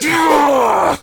slash_hard2.ogg